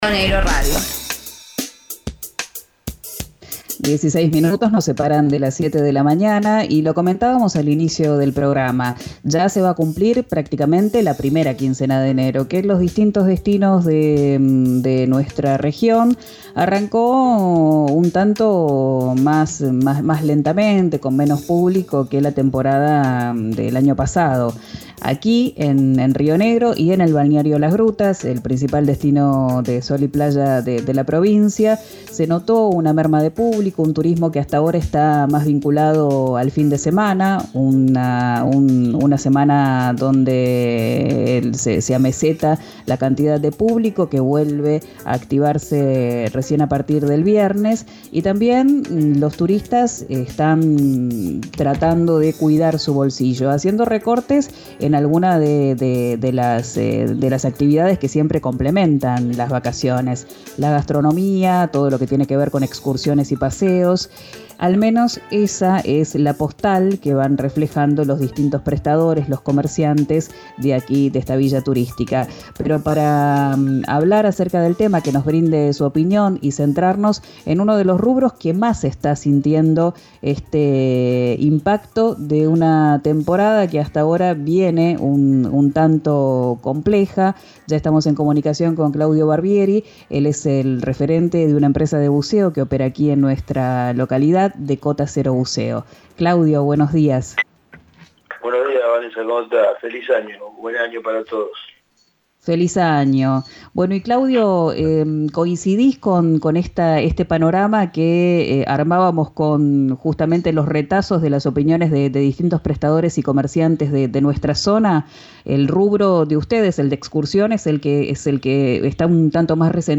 que habló con RÍO NEGRO RADIO y analizó la temporada de verano.